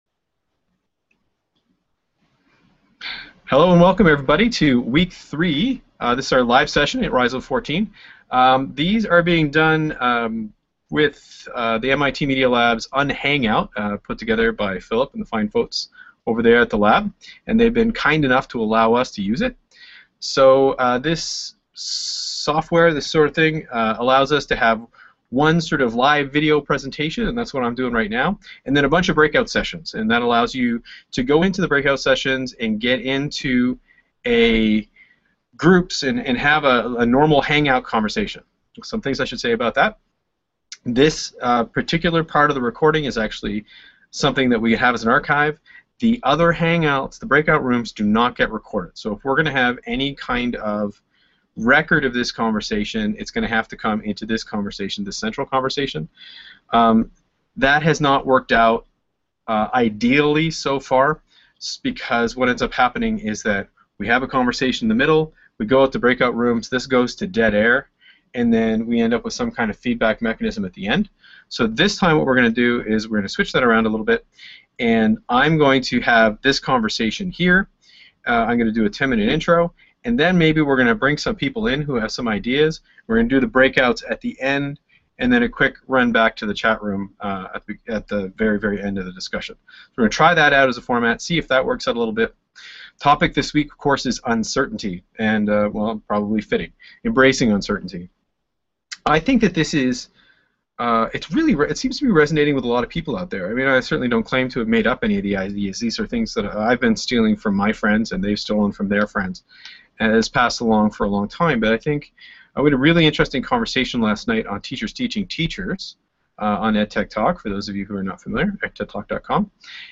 This was an Electronic Village Online event for Week 5 (Focus) in MultiMOOC EVO session, held jointly with ICT4ELT